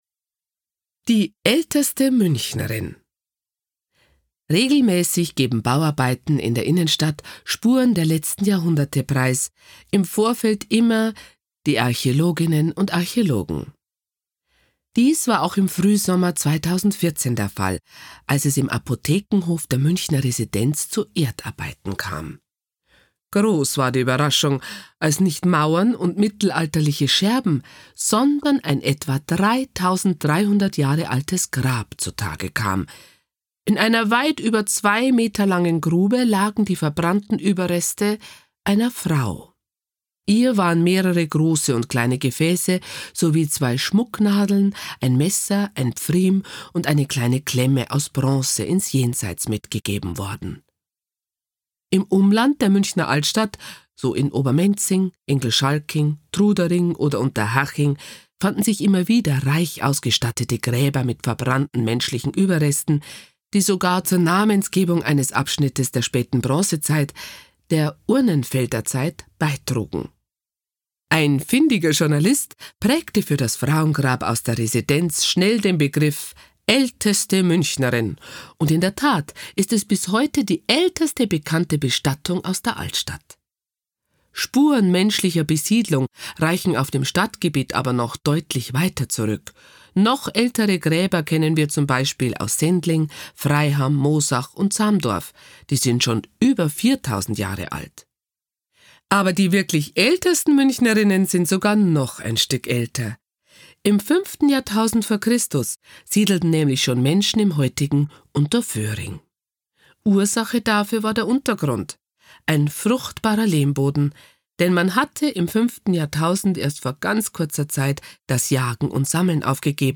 Übrigens: Für alle, die das Bairische lieben, gibt es unsere Schmankerl-Tour. Auf dieser Spezialtour entführt Sie die bekannte Kabarettistin und Komödiantin Luise Kinseher zu den außergewöhnlichsten Funden und Geschichten aus der Vorzeit Münchens – mit einem „Augenzwinkern“ und viel Humor. Lernen Sie die "älteste Münchnerin" kennen und erfahren Sie, warum Bier in Bayern nicht immer ein Verkaufsschlager war.